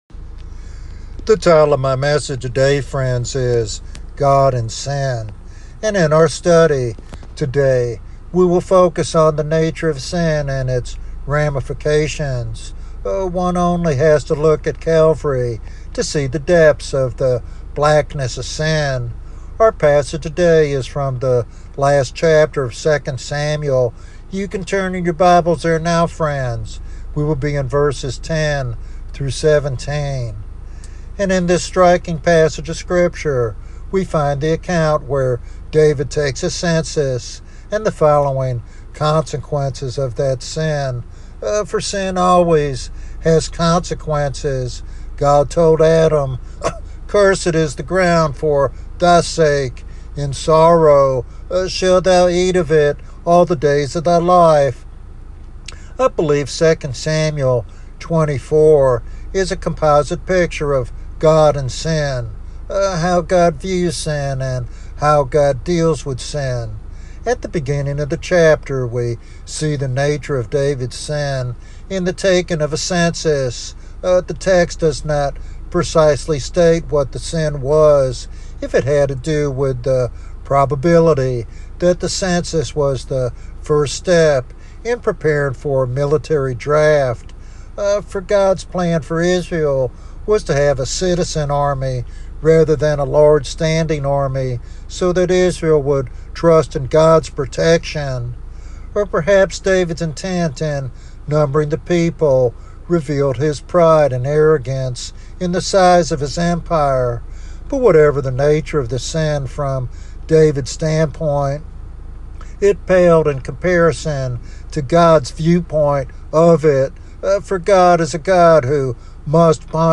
In this expository sermon